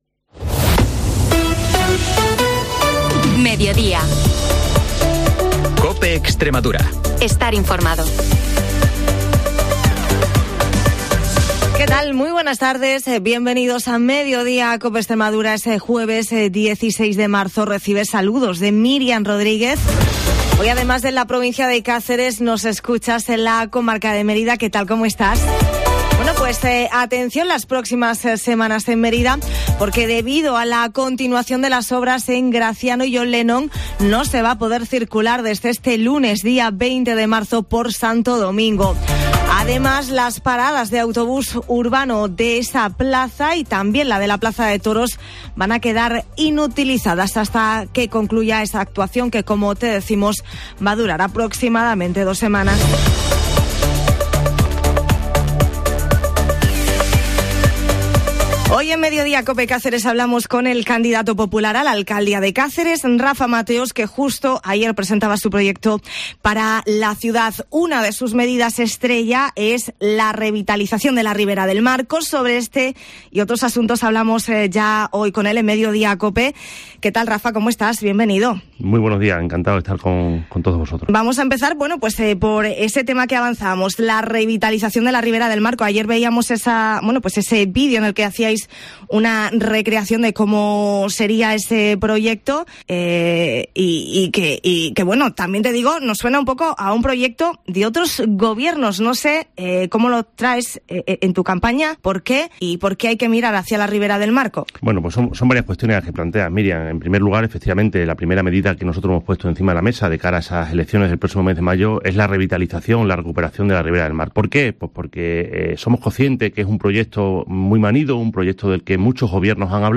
En Mediodía COPE Cáceres hablamos con Rafa Mateos, candidato Popular a la alcaldía de Cáceres